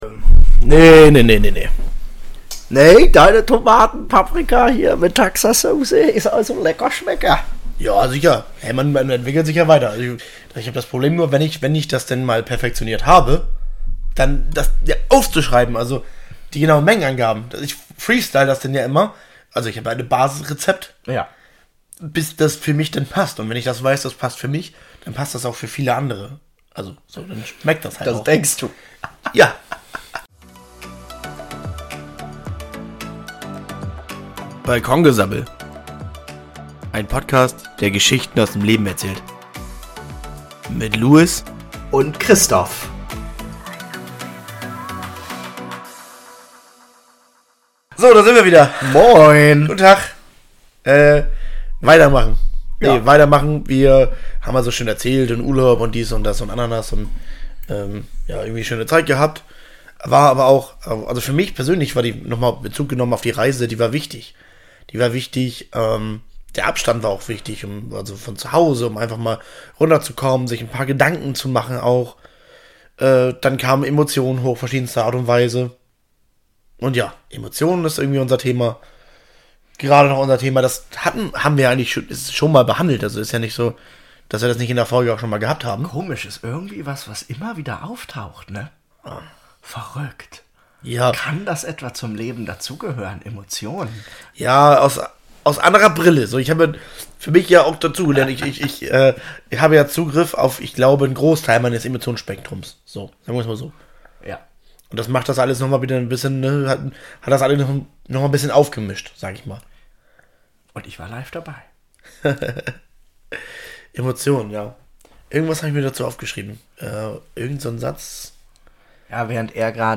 Mal ernst, mal herrlich planlos, aber immer ehrlich.